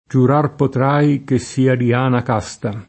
Diana [dL#na] pers. f. — sim. il cogn.